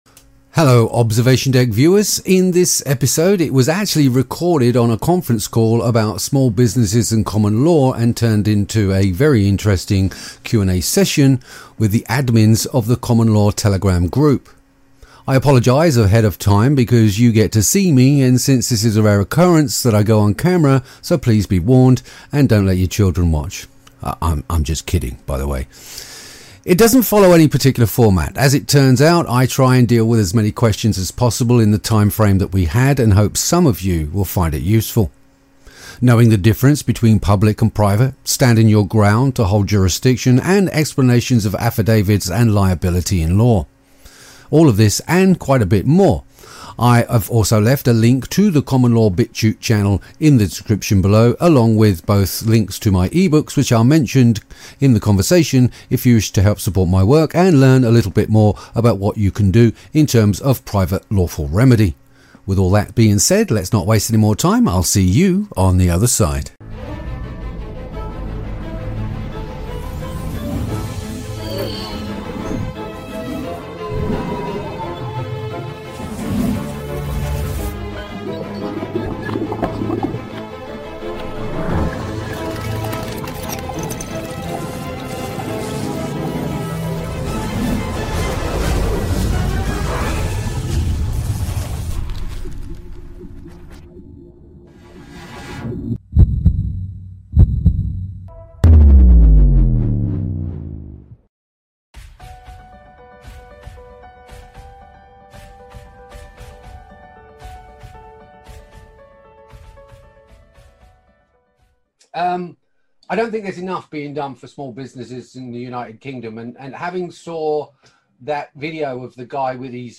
Hi OD viewers, in the FAQ chat live on Zoom I discuss with admins from the Common Law group various aspects of common law, commercial Liens, and standing your ground, especially in these trying times. How to retain jurisdiction and bring a claim in the private.